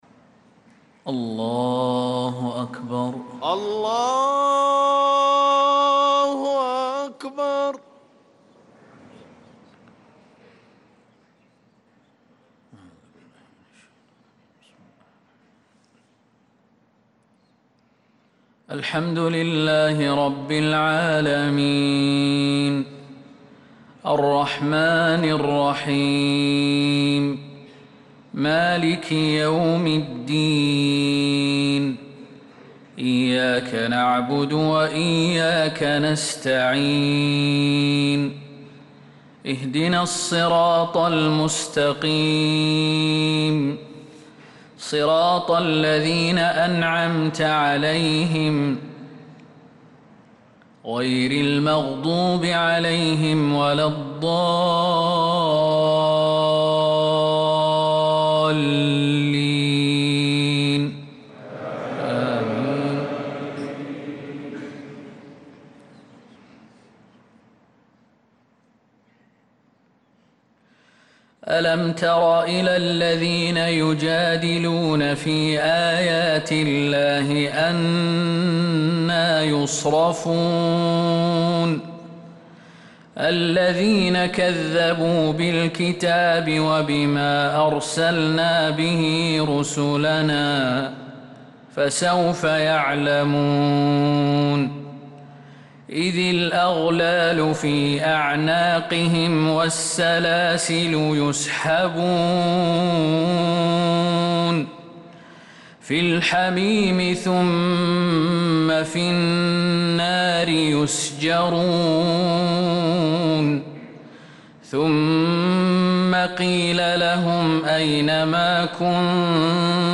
صلاة الفجر للقارئ خالد المهنا 23 ربيع الأول 1446 هـ
تِلَاوَات الْحَرَمَيْن .